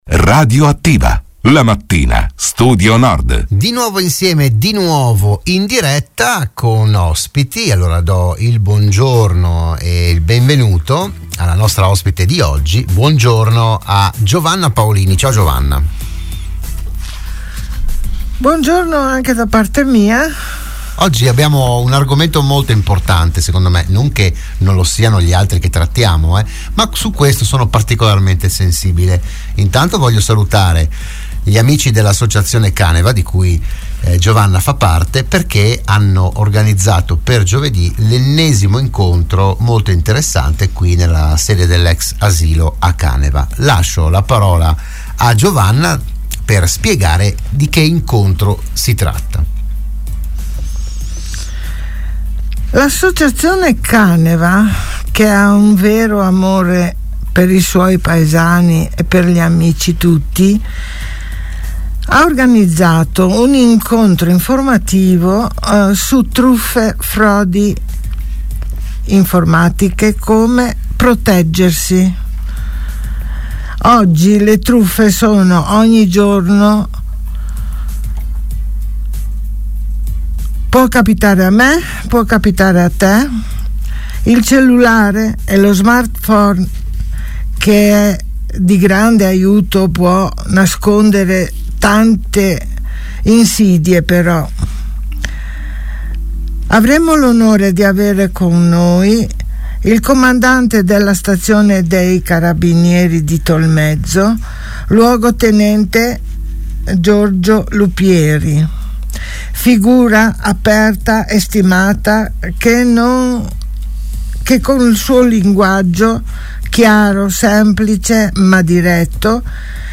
Dell’iniziativa si è parlato oggi a “RadioAttiva“, la trasmissione di Radio Studio Nord